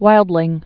(wīldlĭng)